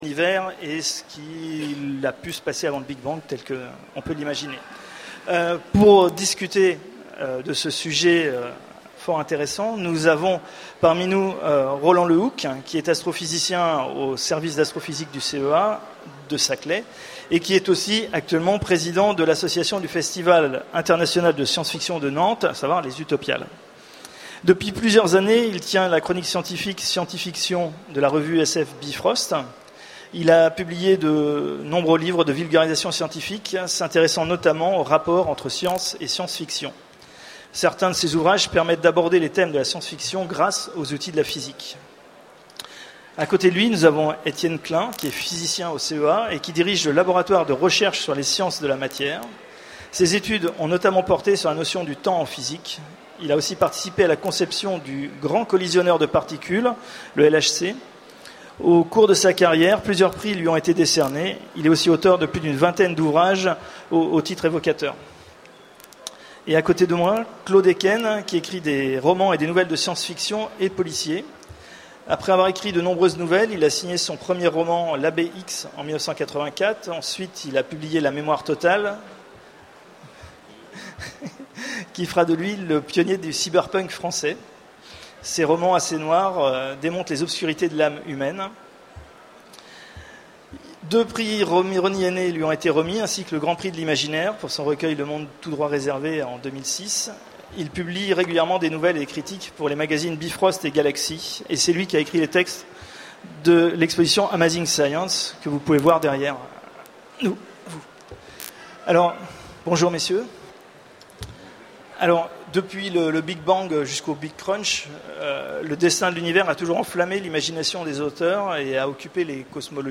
Utopiales 12 : Conférence Les origines de l’univers